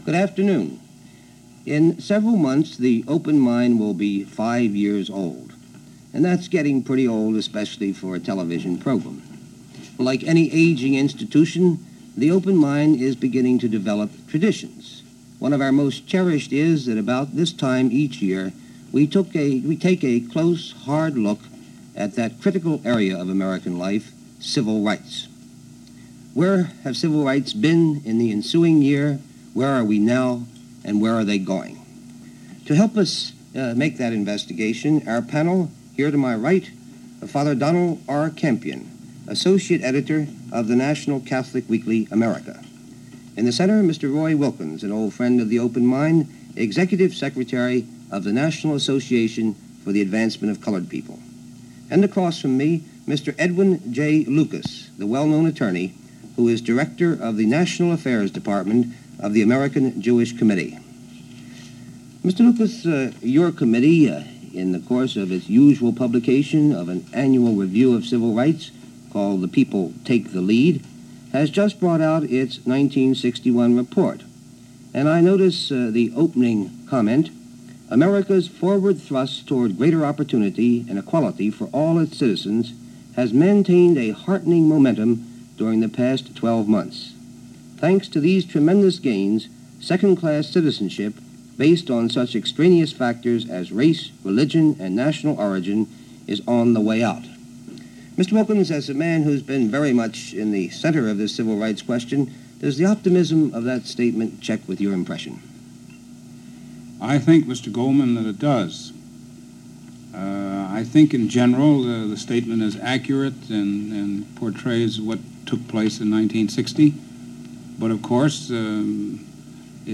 February 26, 1961 - Civil Rights Review: 1960 - A panel discussion on the status of the Civil Rights Movement in the U.S. - February 26, 1961.